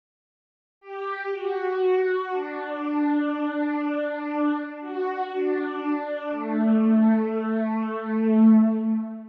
Default Oscillating string sound ...
I think your sounds are too soft and rounded. it's definitely more a saw lead, with more reverb then phase. try a simple string sounds, feed it through some hall delay, and crank some of those newer, juicy, distortions for the edge.